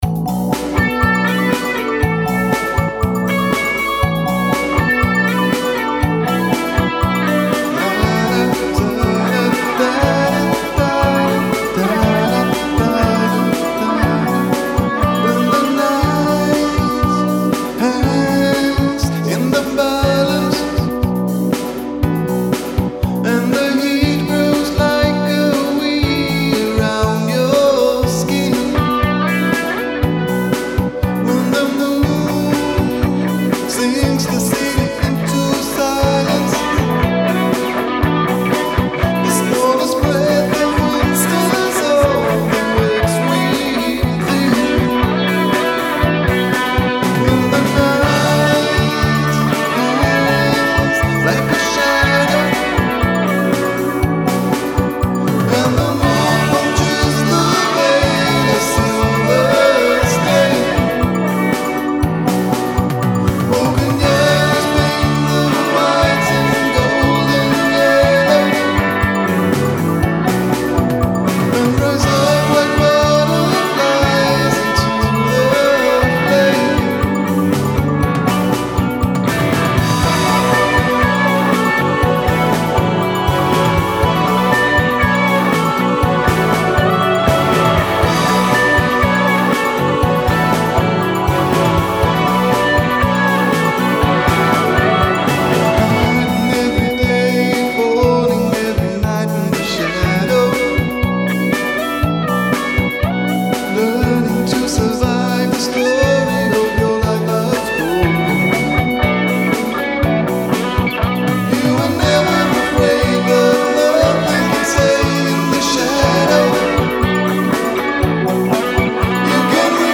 A first demo mix for my (maybe?) 80s pop-rock project.
It was recorded and mixed in Reason Essentials 1.5 and Audition 3 for a little compression and eq on the master wave file. Hohner LG90 guitar, an old former bass guitar, a Shure C606 microphone, freeware and demo .sf2′s available on the net, and an Audigy 2 Platinum soundcard.